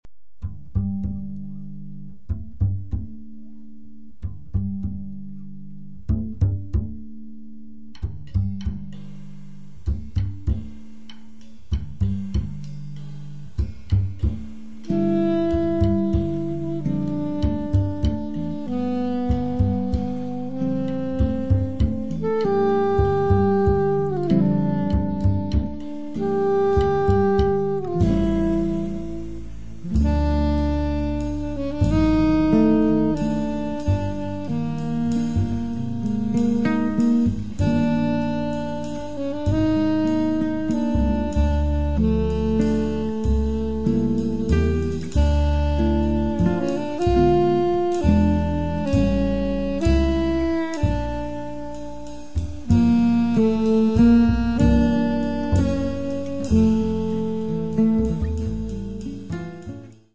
Sax
bass
drums
guitar